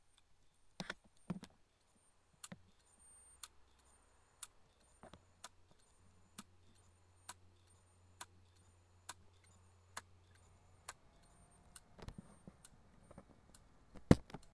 disquedurclac.ogg